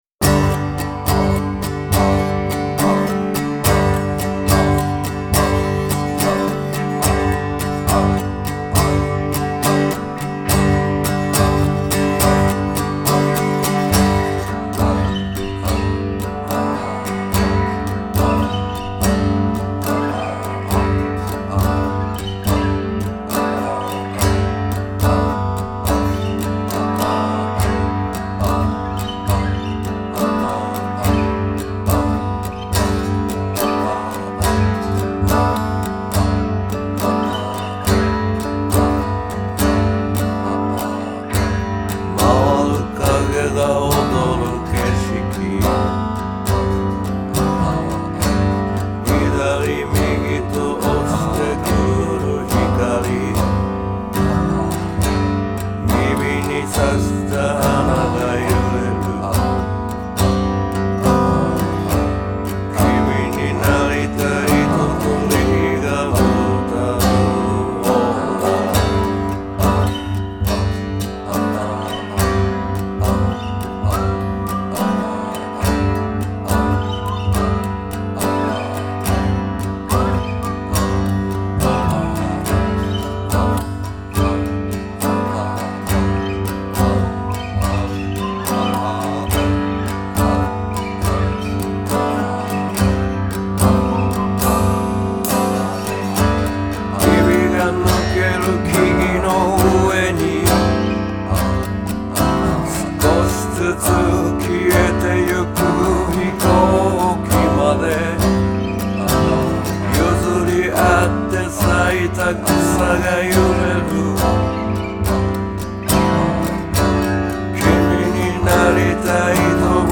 ギター、うた、笛、ピアノ、打楽器